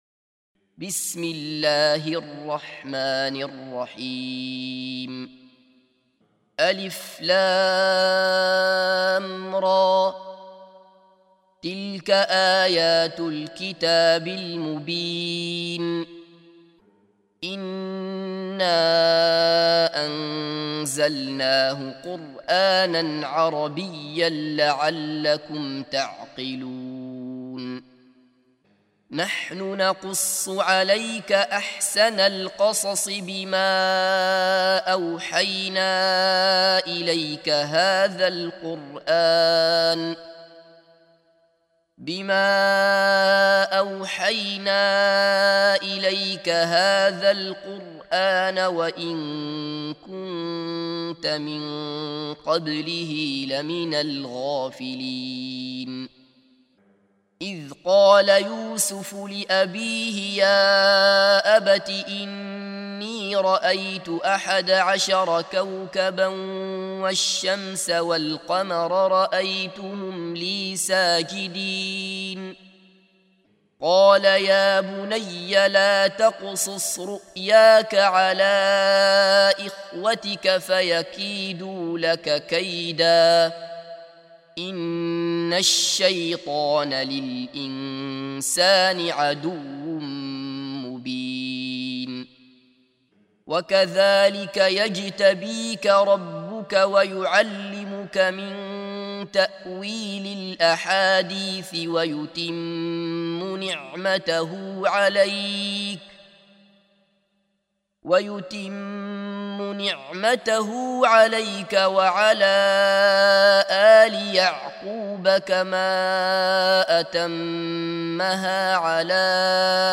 سُورَةُ يُوسُفَ بصوت الشيخ عبدالله بصفر